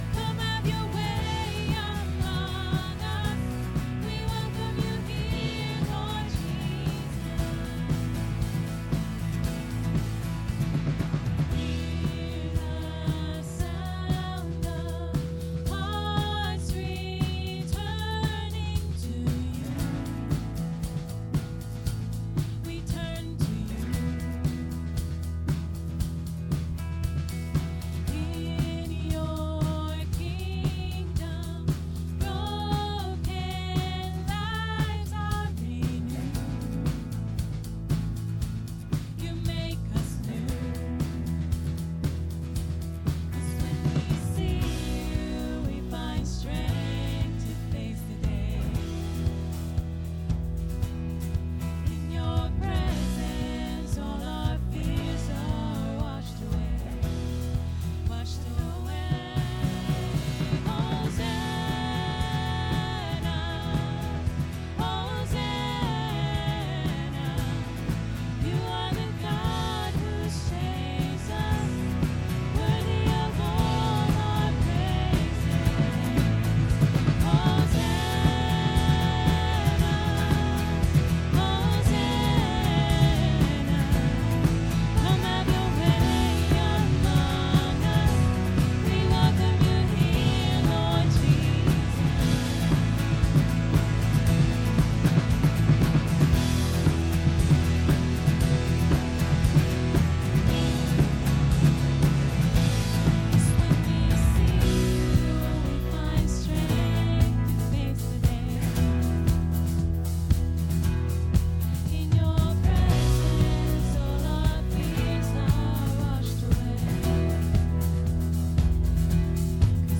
Last Sunday, I gave a message on one of these last kinds that felt a little aimless in my head.